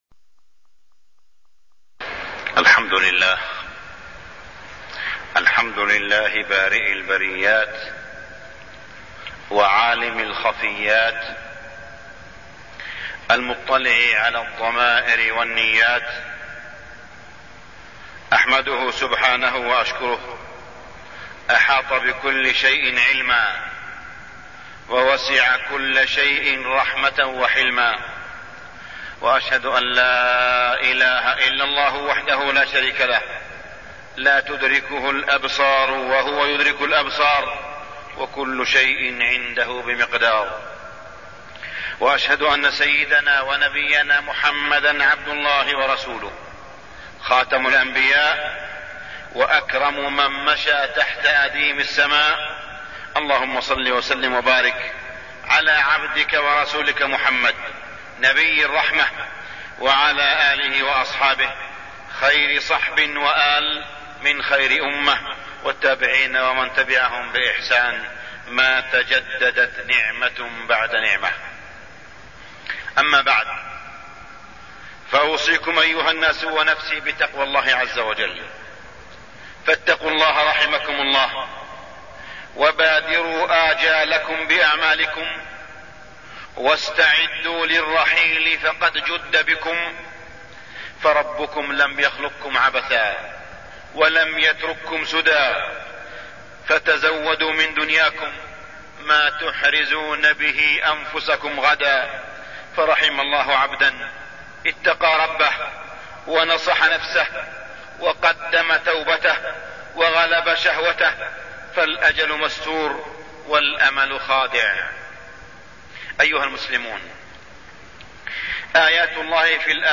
تاريخ النشر ١٣ صفر ١٤٢٠ هـ المكان: المسجد الحرام الشيخ: معالي الشيخ أ.د. صالح بن عبدالله بن حميد معالي الشيخ أ.د. صالح بن عبدالله بن حميد نعمة الماء